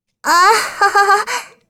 女孩尴尬笑声音效免费音频素材下载